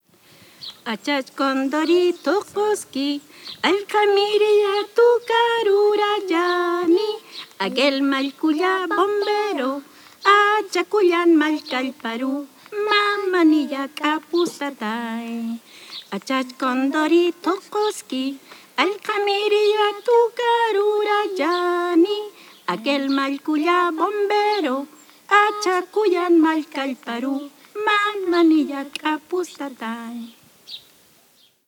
Música aymara (La Huayca, Tarapacá)
Música tradicional
Música vocal